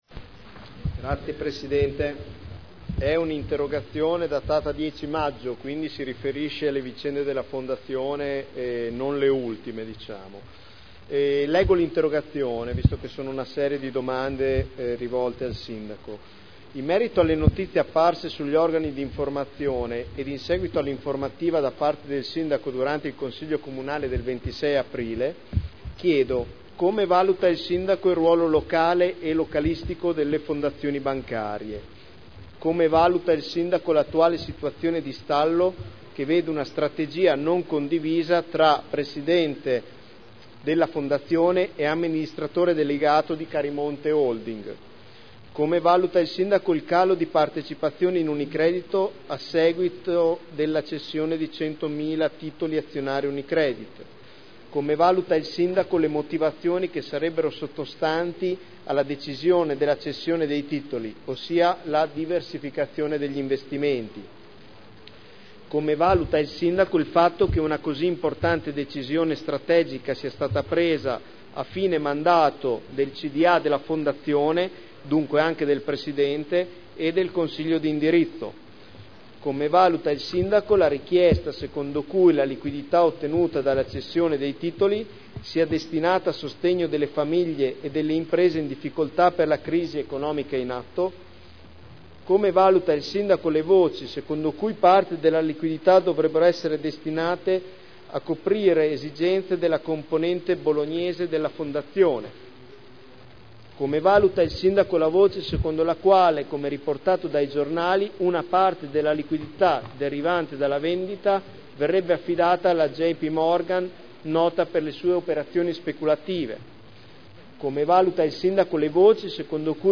Seduta del 25/10/2010. Introduce interrogazione del consigliere Rossi N. (Lega Nord) avente per oggetto: “Fondazione Cassa Modena”